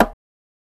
(BONGO) Dro Bongo A.wav